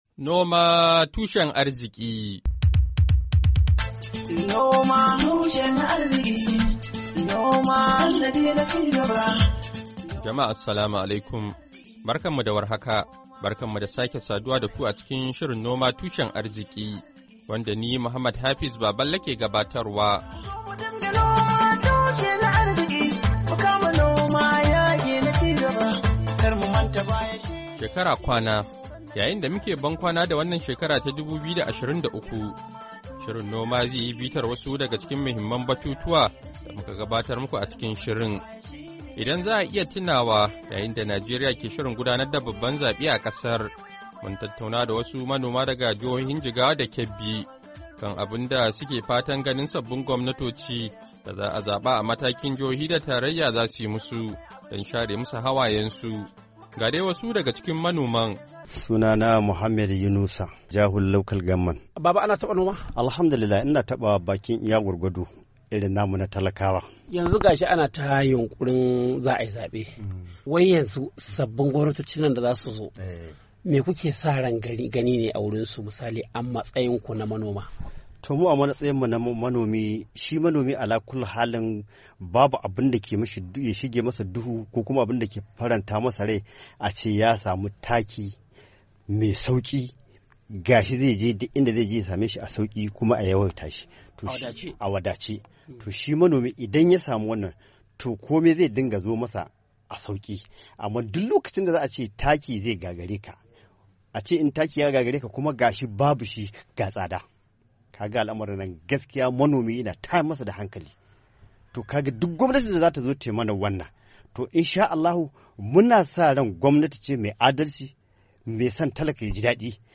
Shirin Noma Tushen Arziki na wannan makon, zai yi bitar daya daga cikin muhimman batutuwa da muka kawo muku a wannan shekara da muke bankwana da ita ta 2023. Shirin ya tattauna da wasu manoma daga jihohin Jigawa da Kebbi, kan abun su ke fatan ganin sabbin gwamnatoci da za’a zaba a Najeriya daga matakin jihohi da tarayya za su yi musu, don share mu su hawayen su.